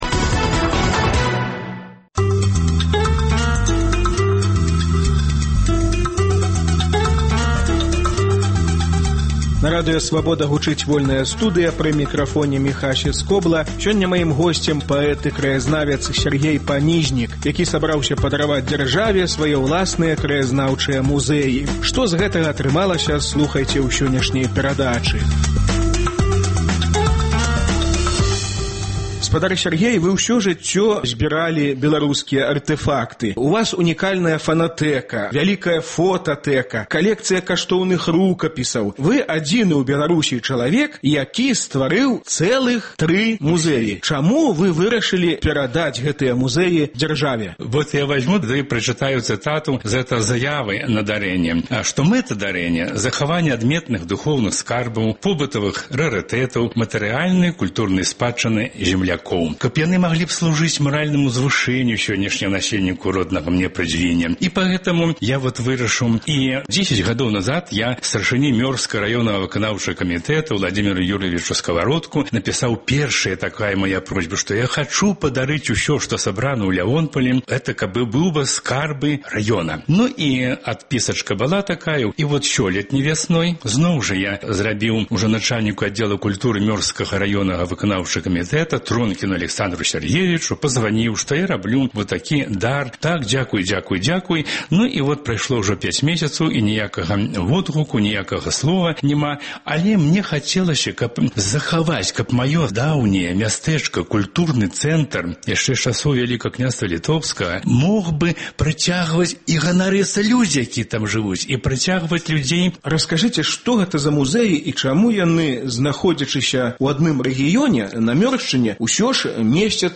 Тры музэі без гаспадара, альбо Чаму радзімазнаўства ня ўпісваецца ў ідэалёгію мёрскіх уладаў? Гутарка з паэтам і краязнаўцам